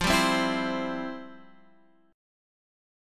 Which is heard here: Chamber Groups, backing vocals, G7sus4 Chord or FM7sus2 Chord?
FM7sus2 Chord